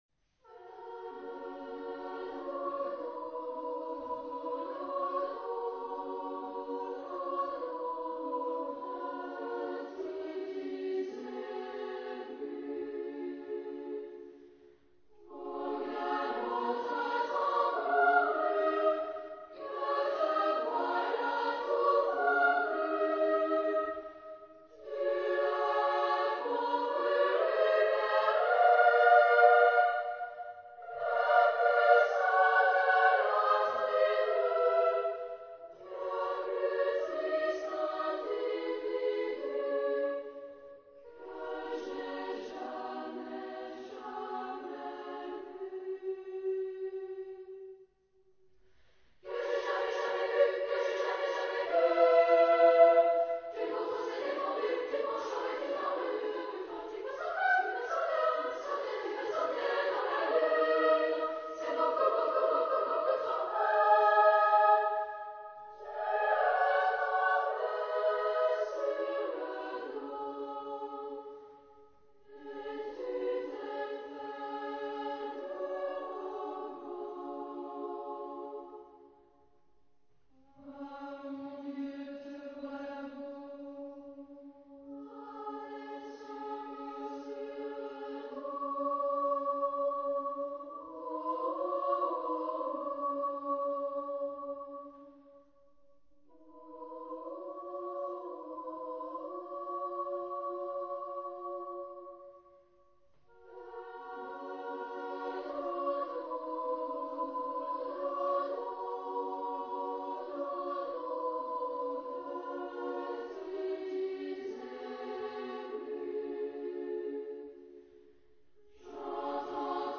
Genre-Style-Form: Partsong ; Secular ; Popular ; Children
Type of Choir: SSA  (3 children OR women voices )